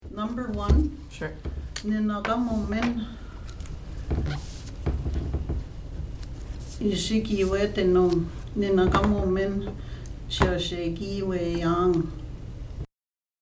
Reading Indigenous Translations of Riel: Heart of the North -- Audio Recordings